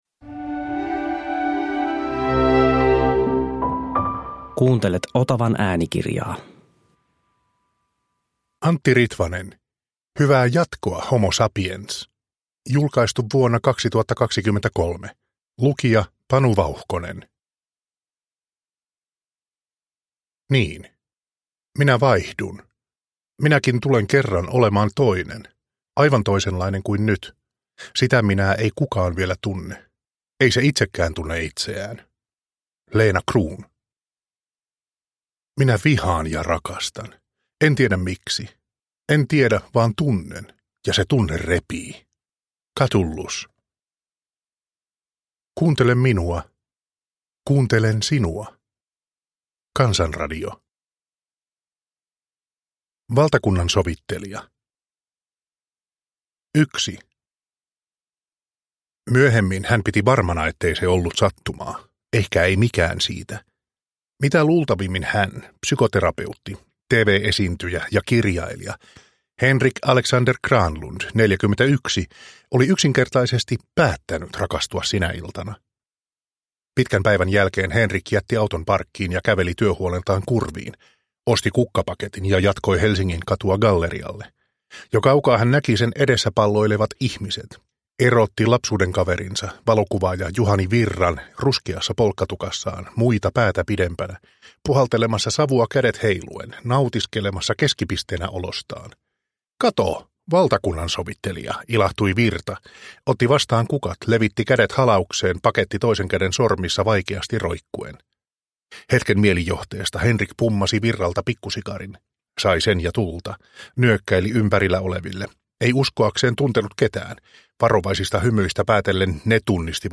Hyvää jatkoa, Homo sapiens! – Ljudbok – Laddas ner